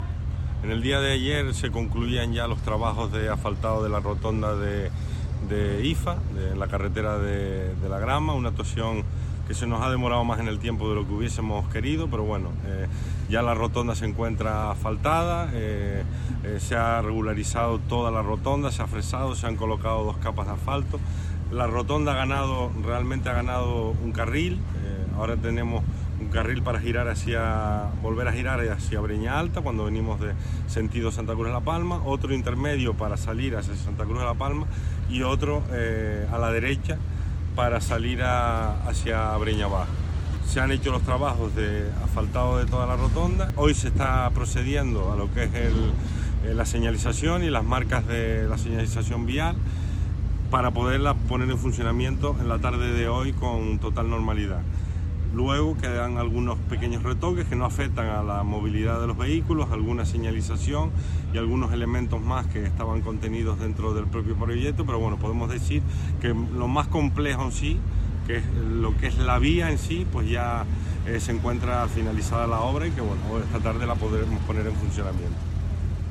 Declaraciones en audio de Borja Perdomo carretera de La Grama.mp3